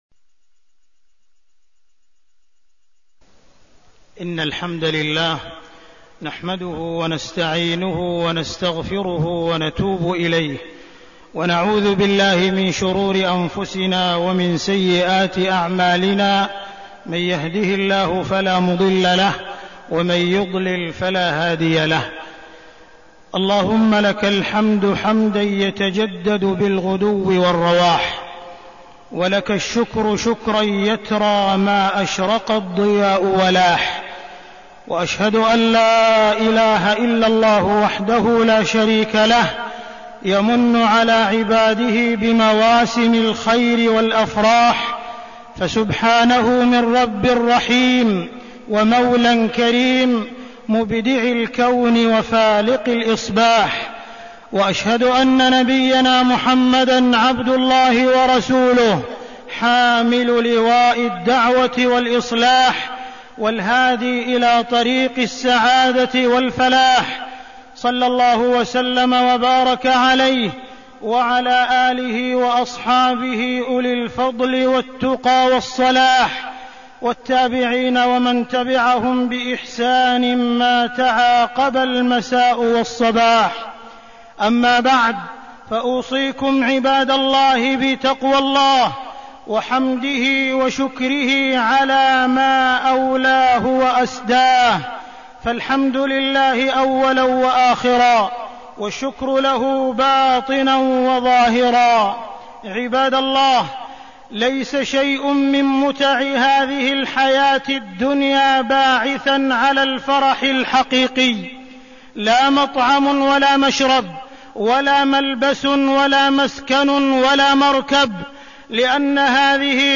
تاريخ النشر ٢٦ شعبان ١٤١٨ هـ المكان: المسجد الحرام الشيخ: معالي الشيخ أ.د. عبدالرحمن بن عبدالعزيز السديس معالي الشيخ أ.د. عبدالرحمن بن عبدالعزيز السديس الفرح بقدوم رمضان